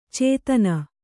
♪ cētana